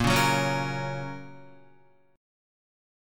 A#m#5 chord